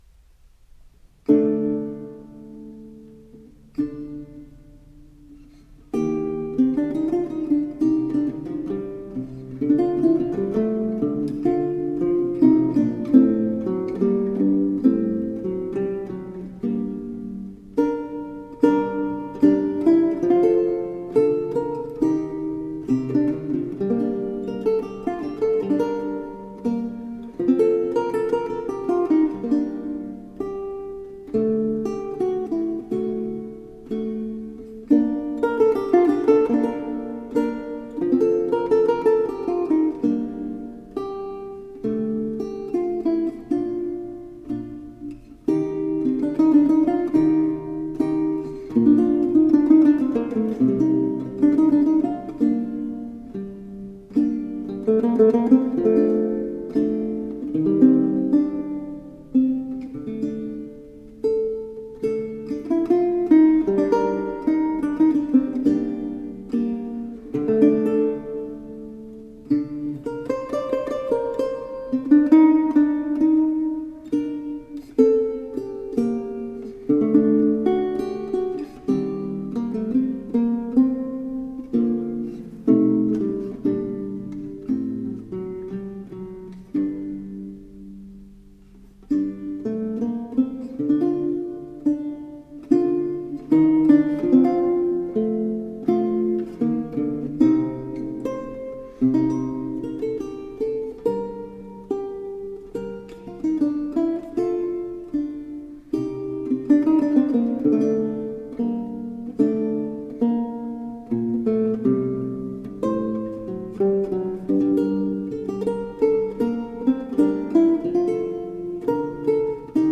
Renaissance lute